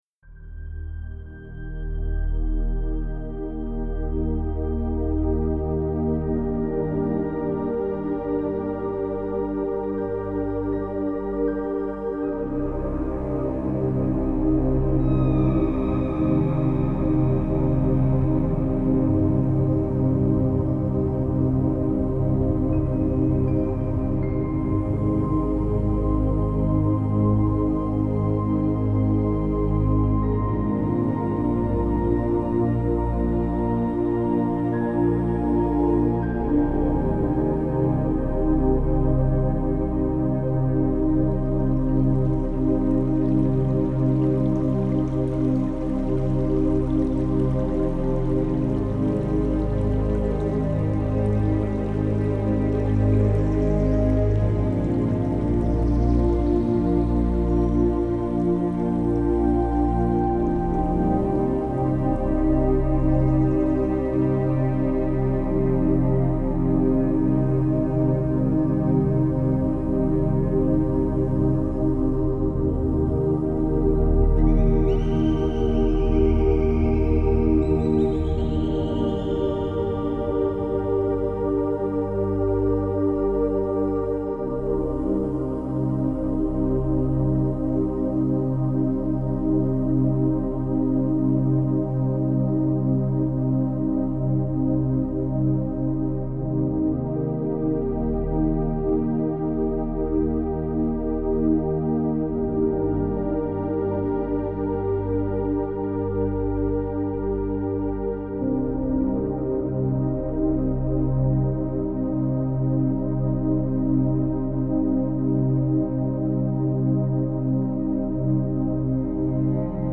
это атмосферная инструментальная композиция в жанре эмбиент